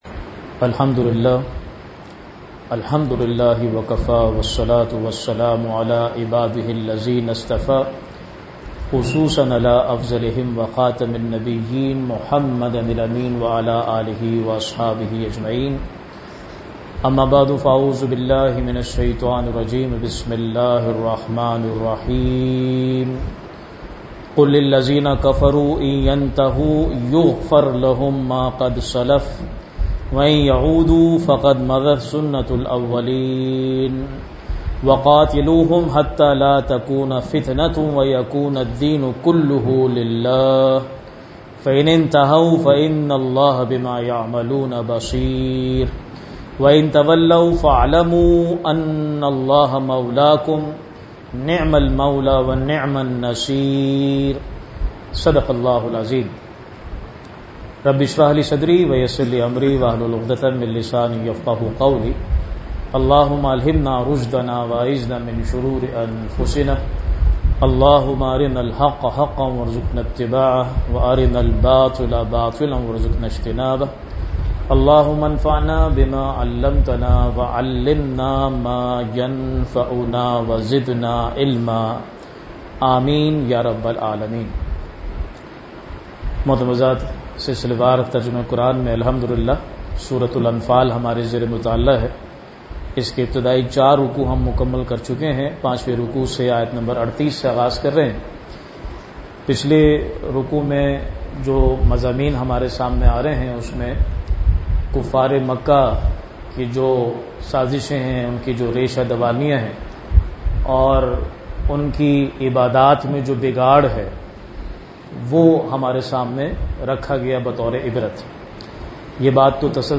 Weekly Dars-e-Quran